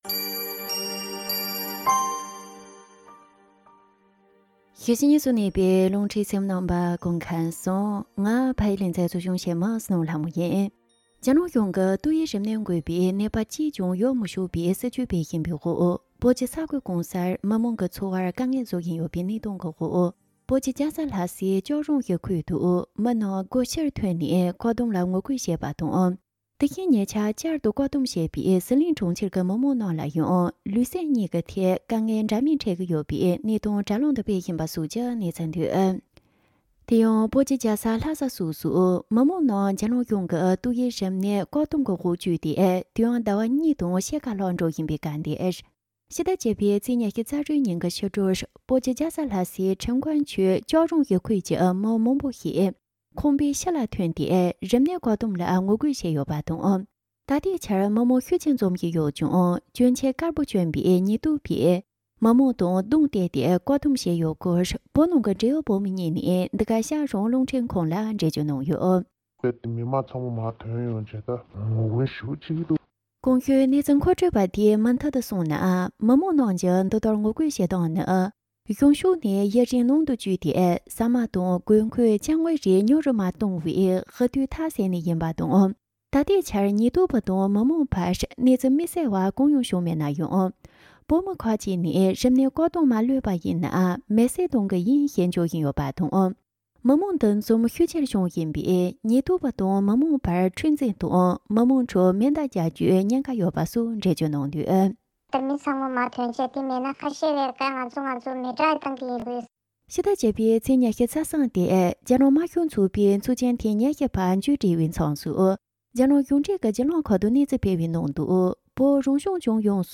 སྒྲ་ལྡན་གསར་འགྱུར། སྒྲ་ཕབ་ལེན།
དེ་རིང་ཕྱི་ཟླ་༡༠ཚེས་༢༦ཉིན་གྱི་ཕྱི་དྲོ་བོད་ཀྱི་རྒྱལ་ས་ལྷ་སའི་ཁྲེང་ཀོན་ཆུས་ལྕགས་རོང་སྡེ་ཁུལ་ཞེས་པར། རྒྱ་ནག་གཞུང་གིས་བོད་མི་ཚོ་འདས་པའི་ཟླ་བ་གཉིས་དང་ཕྱེད་ཀ་རིང་ཏོག་དབྱིབས་རིམས་ནད་བཀག་བསྡོམས་འོག་བཅུག་སྟེ། ཕྱི་ལ་མ་བཏང་བས་ཡུལ་མི་མང་པོ་ཁང་པའི་ཕྱི་ལ་ཐོན་སྟེ་རིམས་ནད་བཀག་བསྡོམས་ལ་ངོ་རྒོལ་བྱས་ཡོད་པ་དང་། ད་ལྟའི་ཆར་མི་མང་ཤུགས་ཆེར་འཛོམས་བཞིན་ཡོད་ཀྱང་གྱོན་ཆས་དཀར་པོ་གྱོན་པའི་ཉེན་རྟོག་པས་མི་མང་དང་གདོང་གཏད་དེ་བཀག་འགོག་བྱས་ཡོད་སྐོར། བོད་ནང་གི་འབྲེལ་ཡོད་བོད་མི་གཉིས་ནས་འགྲེལ་བརྗོད་གནང་བྱུང་།